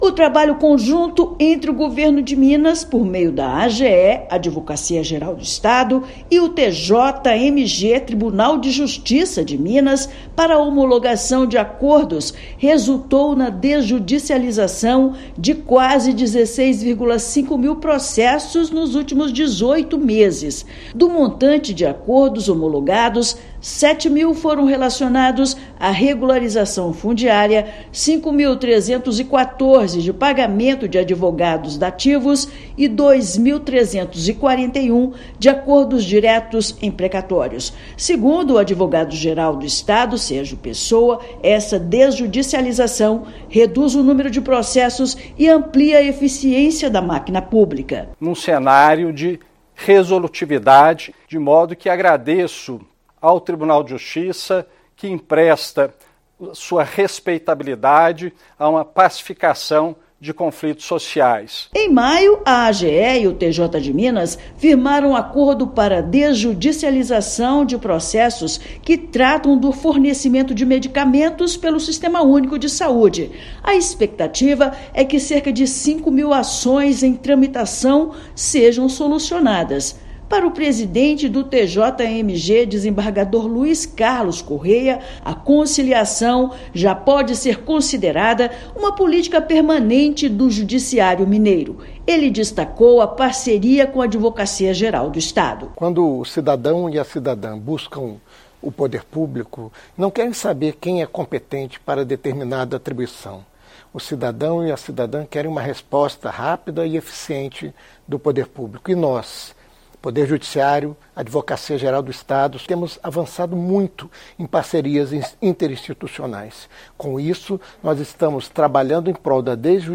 Regularização fundiária e pagamentos de advogados dativos lideram ranking da desjudicialização no estado. Ouça matéria de rádio.